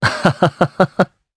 Esker-Vox_Happy3_jp_b.wav